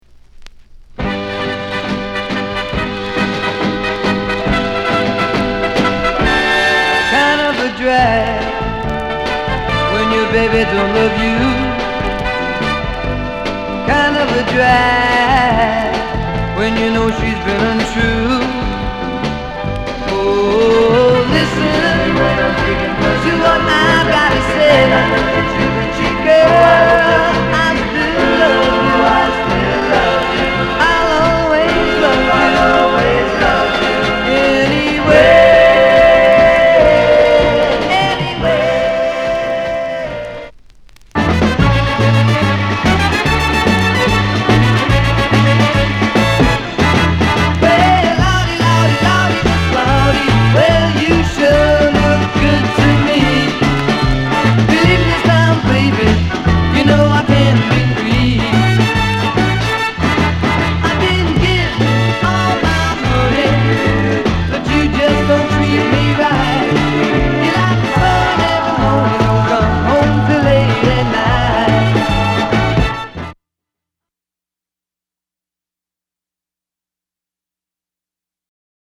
ロック、ポップス（洋楽）